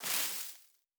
added stepping sounds
Tall_Grass_Mono_02.wav